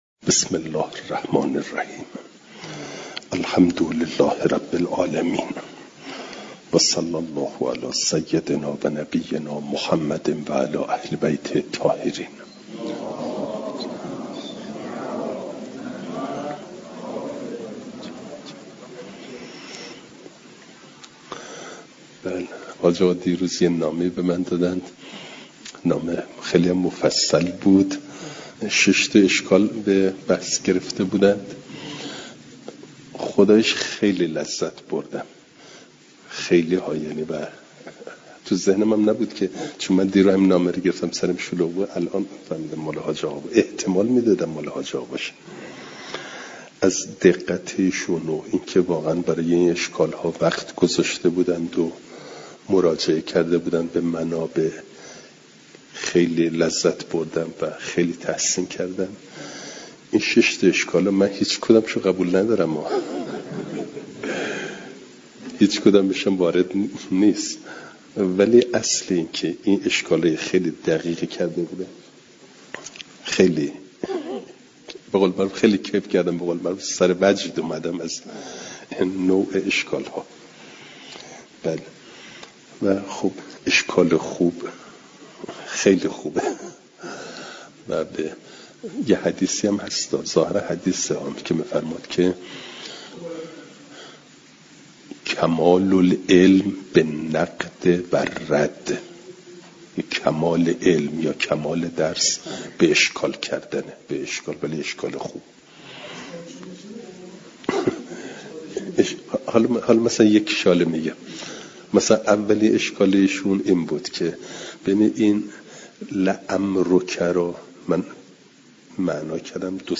جلسه سیصد و شصت و سوم درس تفسیر مجمع البیان